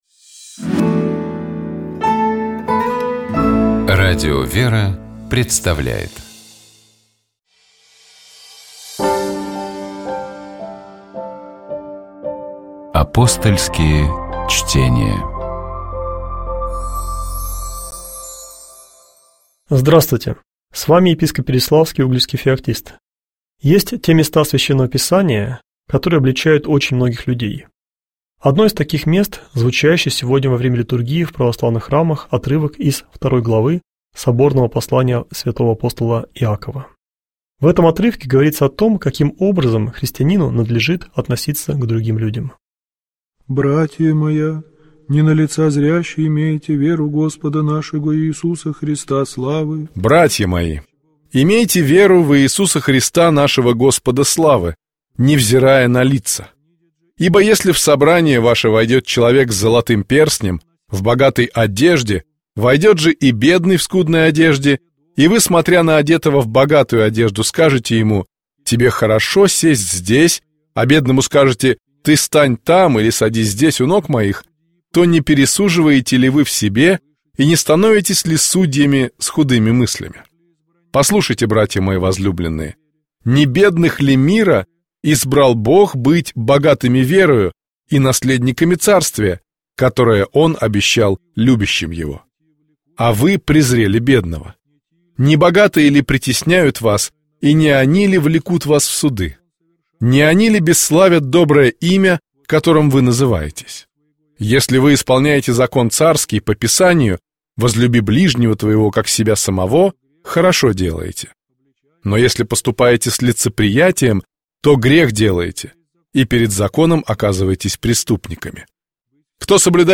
О том, с какими чаяниями и надеждами создавался Иоанновский монастырь, как он развивался, и о том, чем живет одна из самых многочисленных женских обителей России в наши дни, наша программа. О дорогом Батюшке, Святом Праведном Иоанне Кронштадтском, который вел за собой тысячи людей, и является образцом пастырского служения, рассказывают насельницы обители, священнослужители и прихожане.